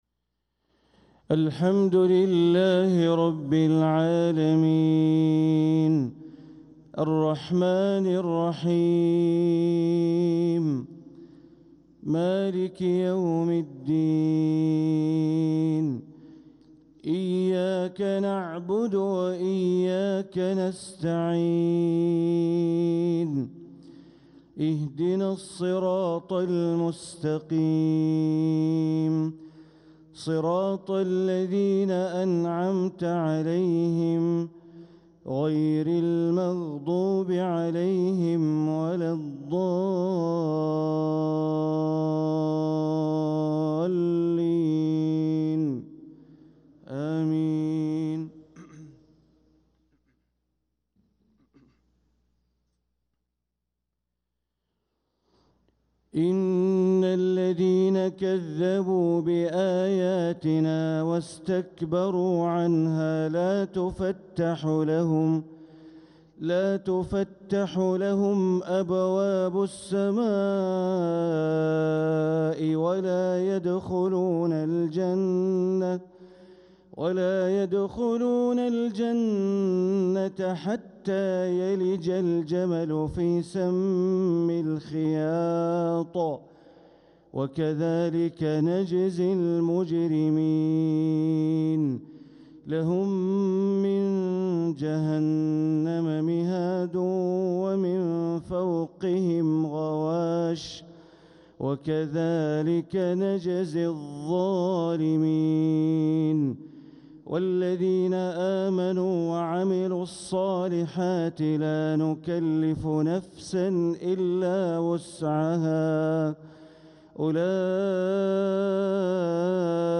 صلاة الفجر للقارئ بندر بليلة 19 ربيع الآخر 1446 هـ
تِلَاوَات الْحَرَمَيْن .